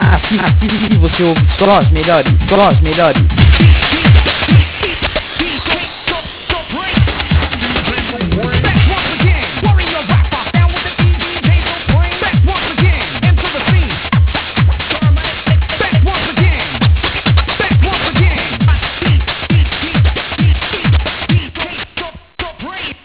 Aqui Algumas De Nossas Vinhetas e Chamadas
Todas Produzidas Pelos Dj's Da Rádio